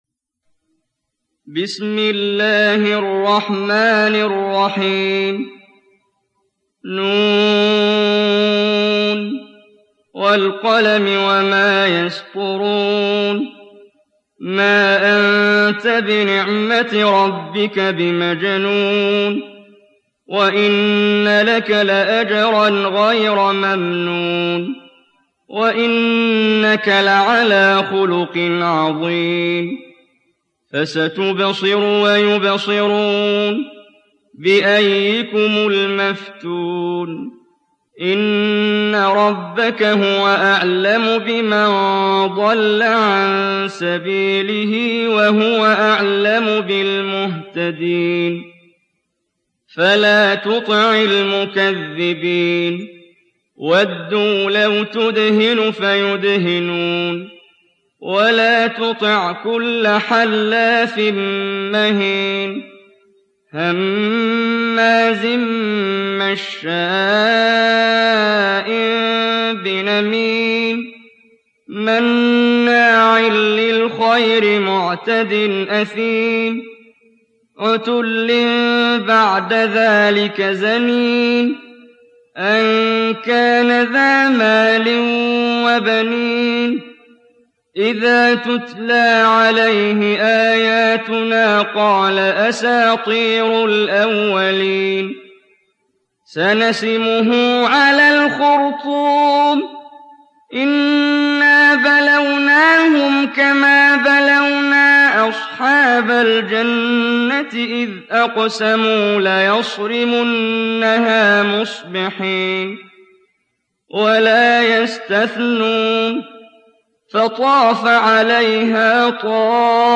Kalem Suresi mp3 İndir Muhammad Jibreel (Riwayat Hafs)